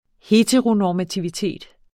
Udtale [ ˈheːtʁonɒːmatiwiˌteˀd ] eller [ ˈhetəʁonɒːmatiwiˌteˀd ]